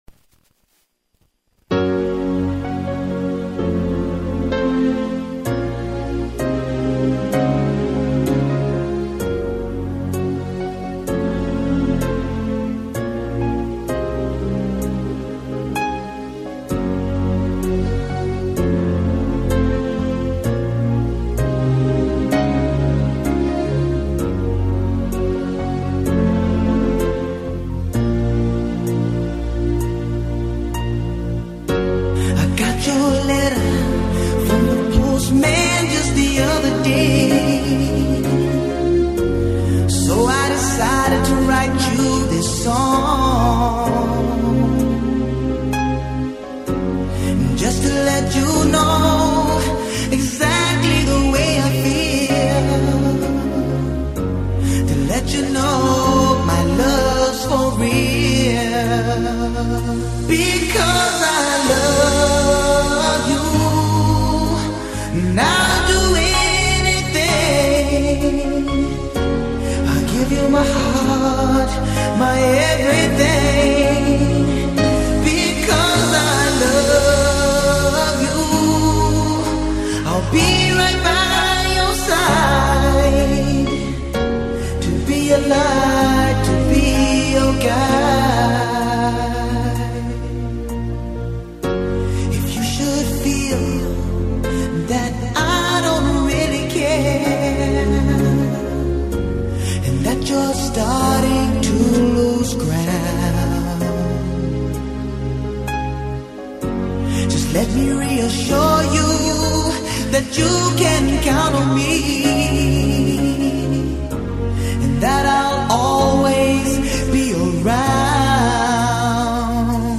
light dance-pop sound